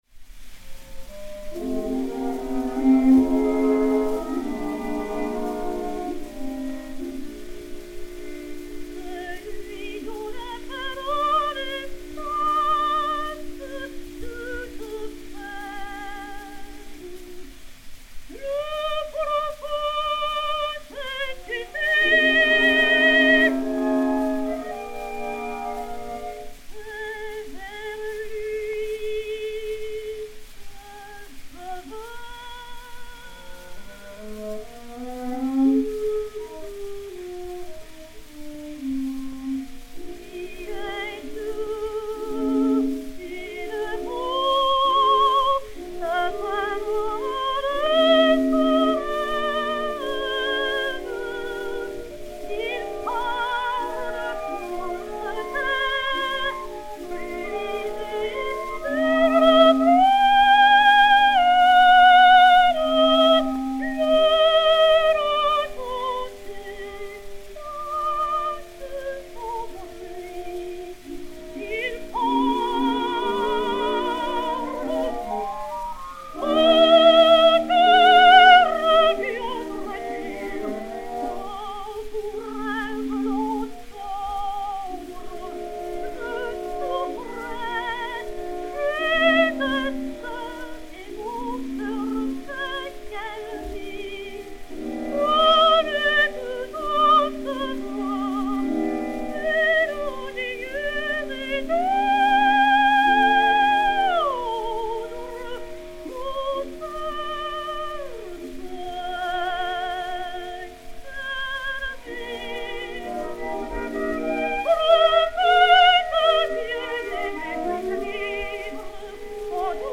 Emma Calvé (Salomé) et Orchestre
C-6012, enr. à New York, le 05 mars 1908